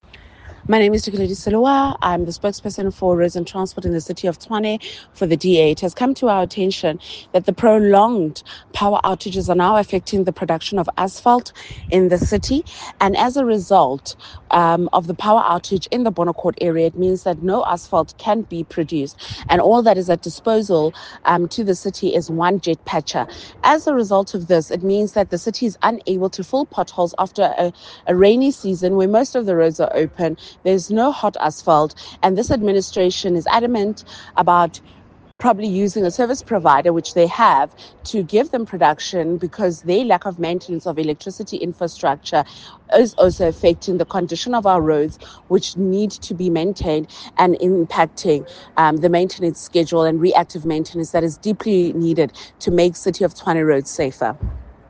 English soundbite by Cllr Dikeledi Selowa